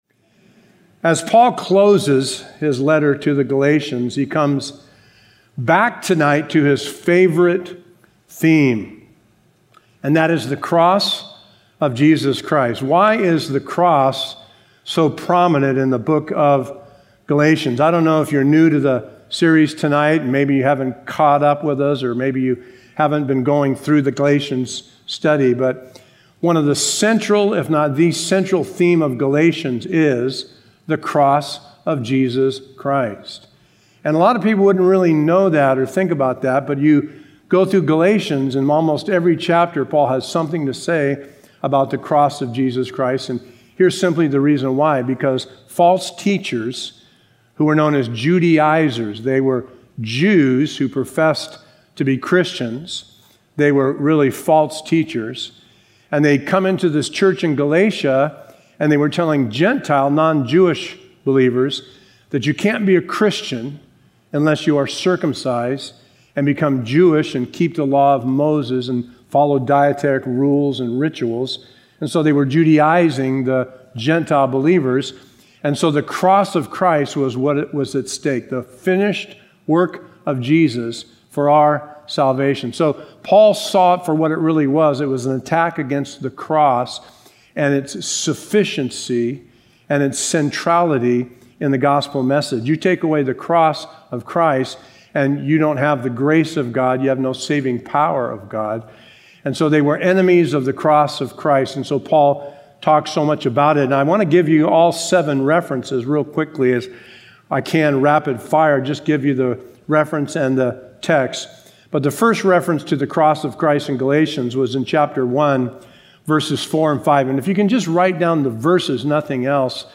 Sermon info